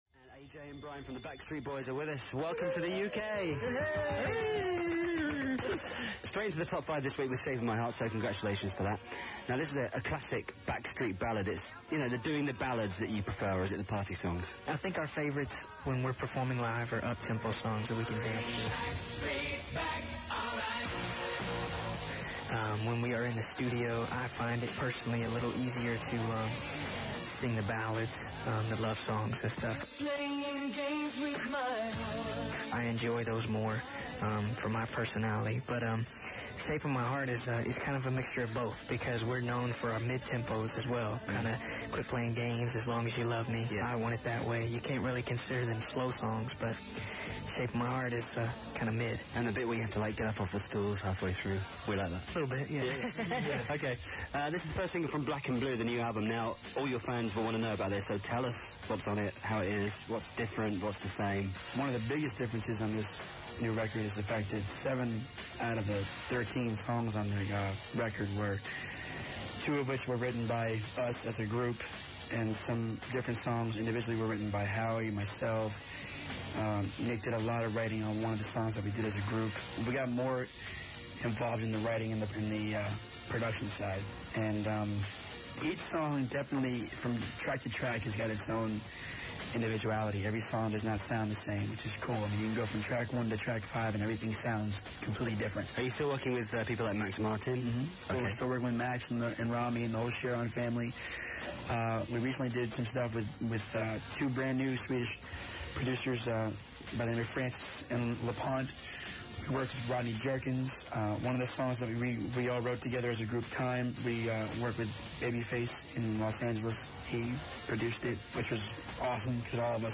THE BBC UK RADIO 1 INTERVIEW Source: BBC UK Radio 1 The Backstreet Boys talked to Scott Mills on the Top of The Pops Radio Show to chat over their music, and perfoming live!